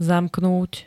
Zvukové nahrávky niektorých slov
44jq-zamknut.ogg